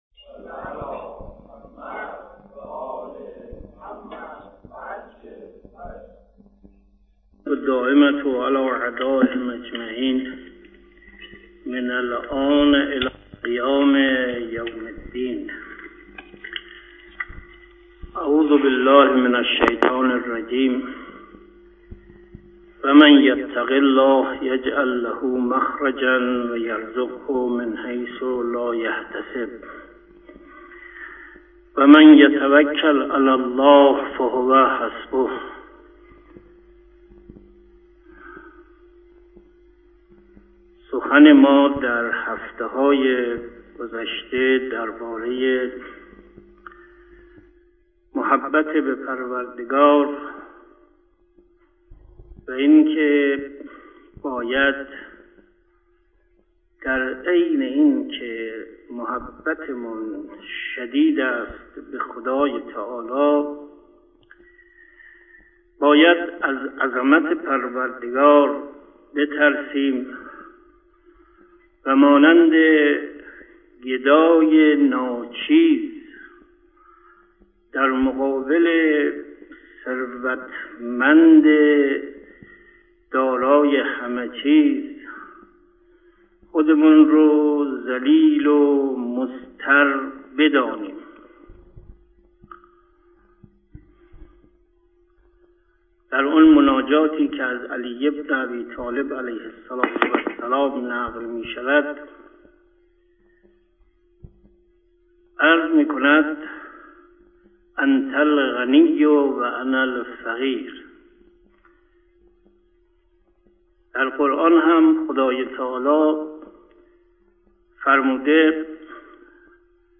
استاد عزیزمان در این مجلس هفتگی که در حدود سی شهر صدای‌شان پخش می گردید در ادامه مباحث مربوط به مرحله محبت در این هفته راجع به این موضوع که محبت خدا به ما، مساله مهم و قابل توجهی است اما چه کنیم که خدای متعال ما را دوست بدارد مطالب و مباحثی مطرح فرمودند.